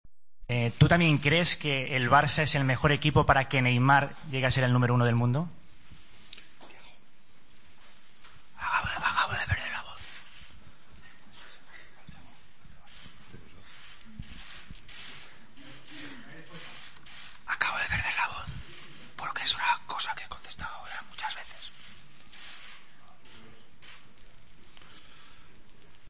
Luis Enrique ha fingido quedarse sin voz tras la pregunta de un periodista de si el Barcelona era el lugar ideal para Neymar.